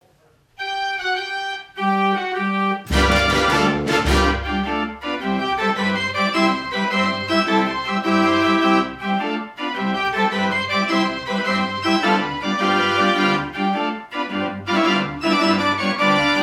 It has 54 keys and plays from paper rolls of music.